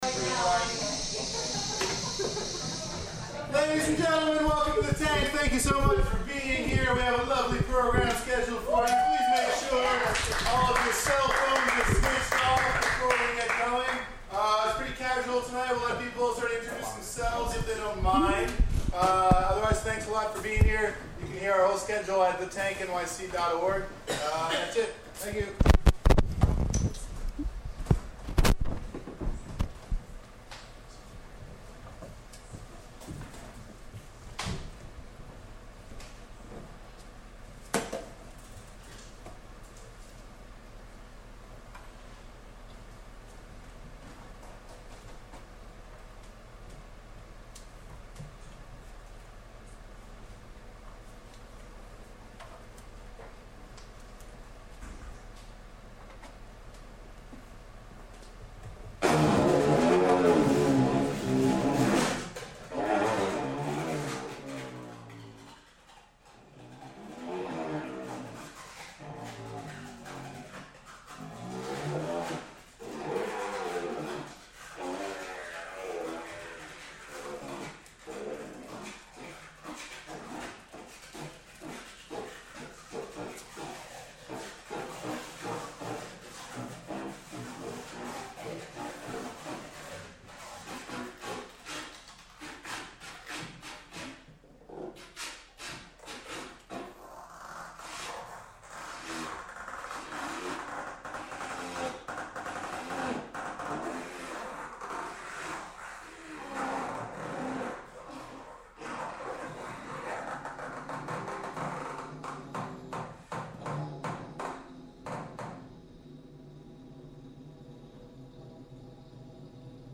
reeds